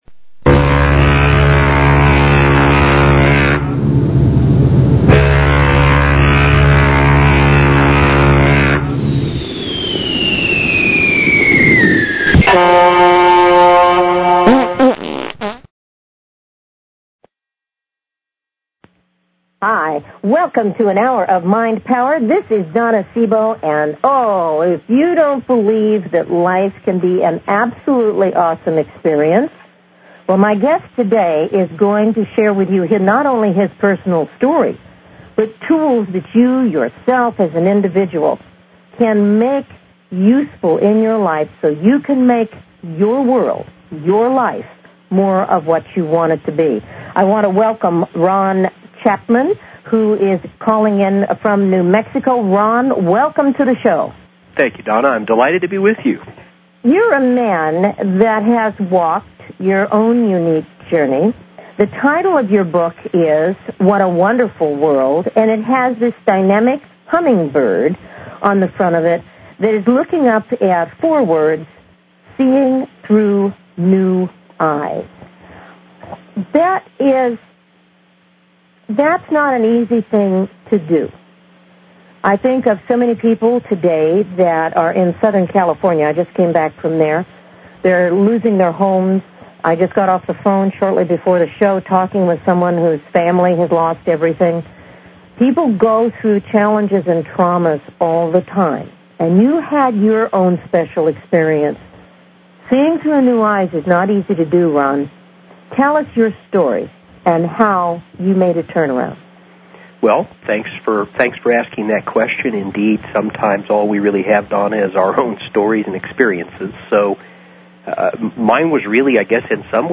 Talk Show Episode, Audio Podcast
With all of the pessimism expounded by so many join me for an upbeat conversation with an optimist. In fact, there will be two of us sharing the airwaves.